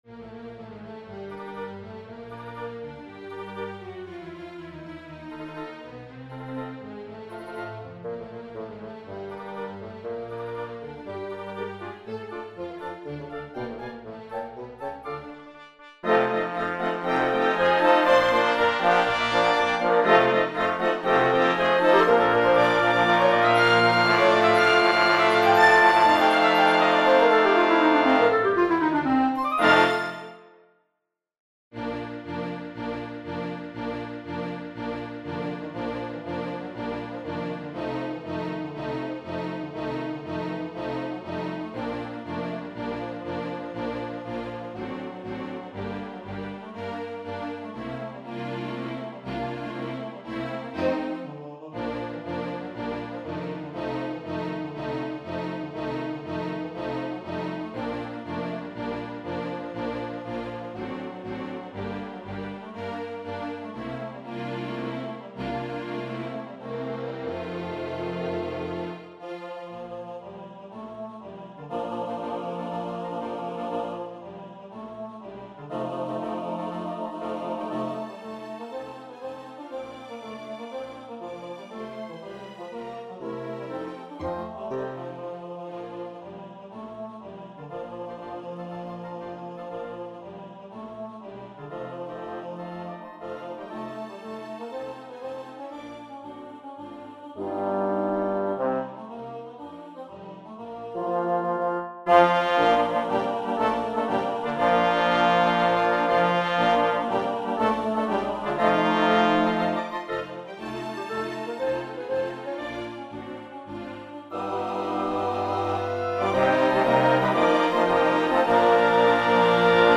Governor and chorus